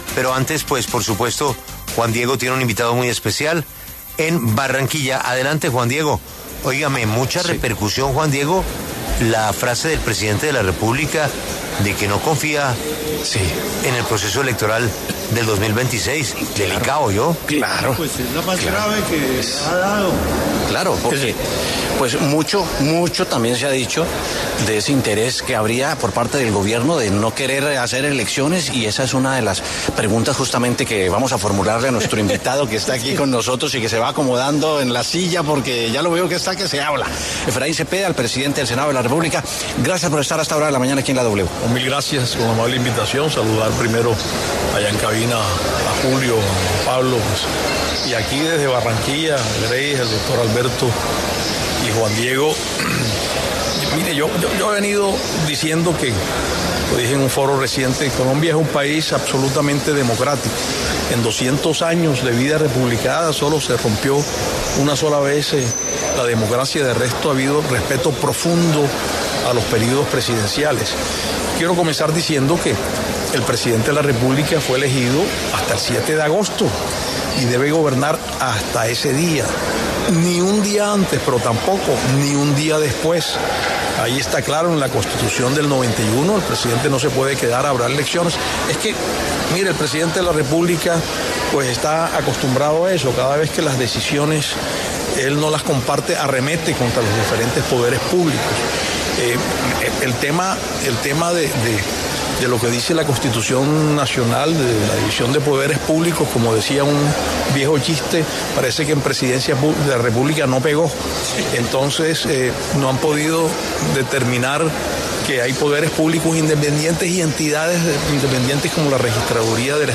Efraín Cepeda, presidente del Senado, habló con La W desde el Foro de Desarrollo Local de la OCDE 2025 que se lleva a cabo en Barranquilla.